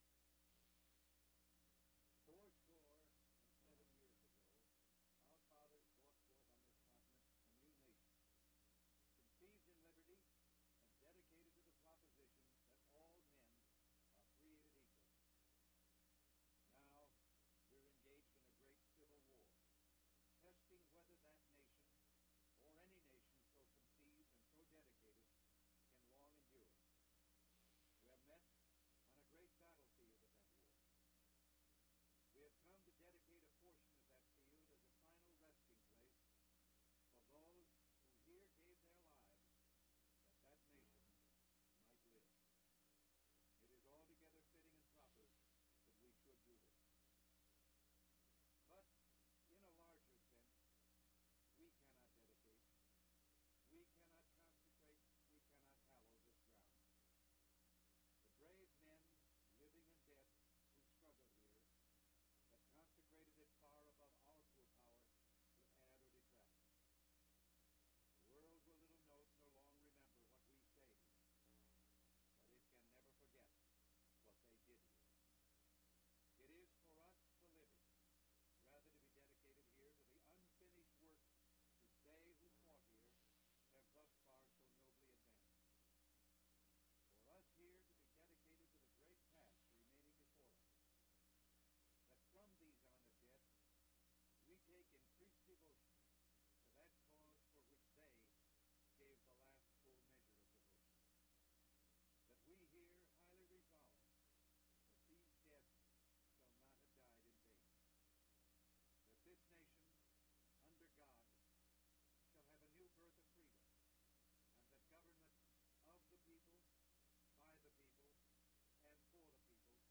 Ronald Reagan recites Gettysburg Address (2 takes)
Audio Cassette Format.